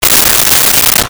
Open Shower Curtain 01
Open Shower Curtain 01.wav